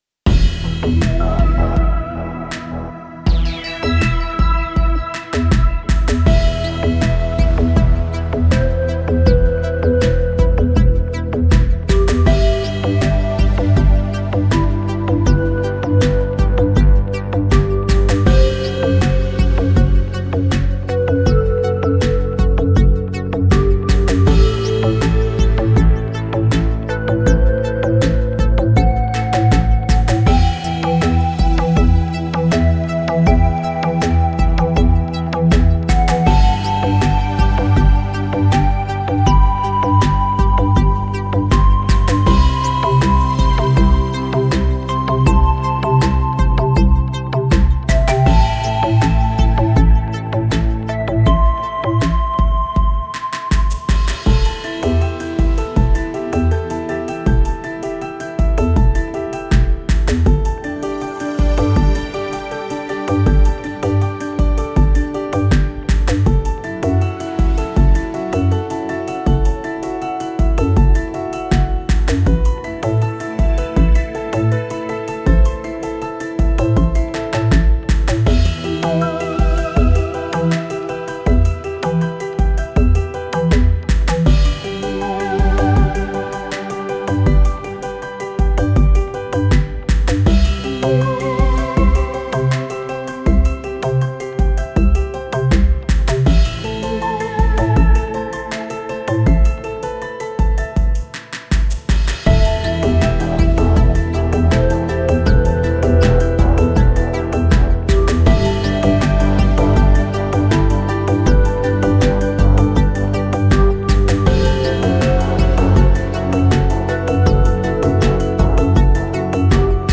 These tracks have been sequenced on Linux with Rosegarden.
Calf Equalizer 12 Bands, Calf Limiter, Calf Reverb,